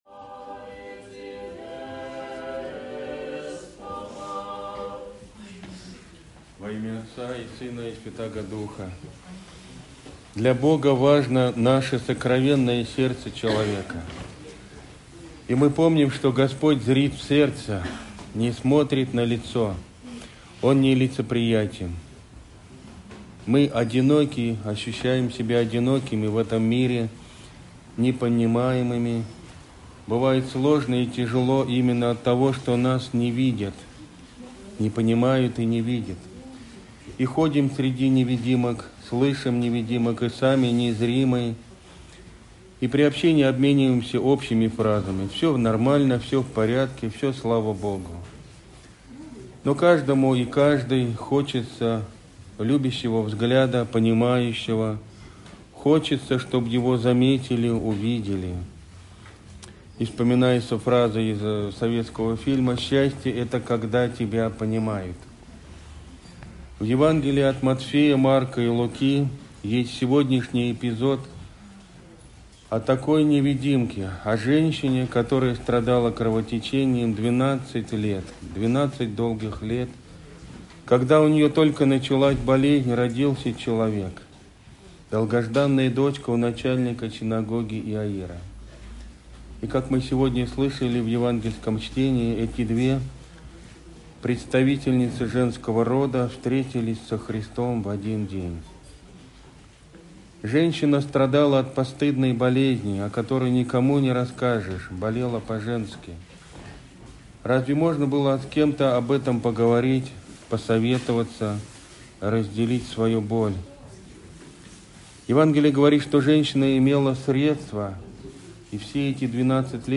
Аудиопроповеди